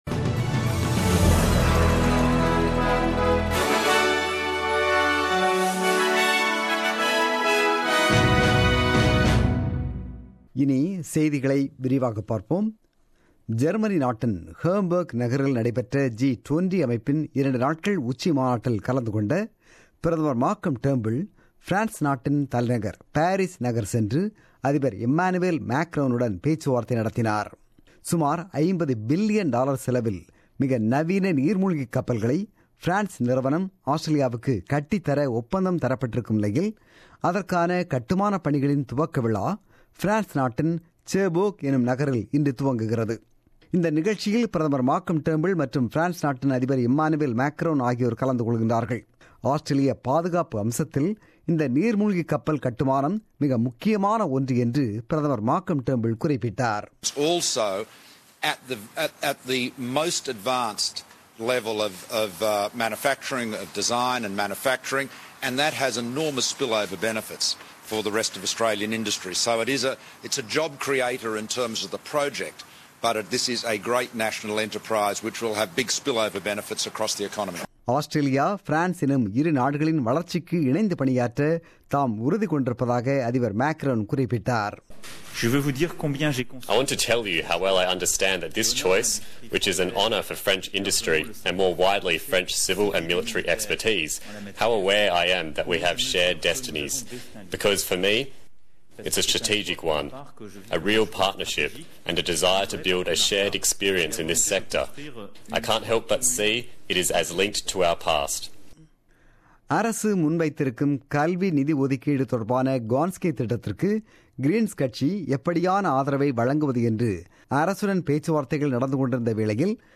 The news bulletin broadcasted on 9 July 2017 at 8pm.